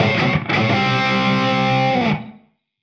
AM_HeroGuitar_85-C01.wav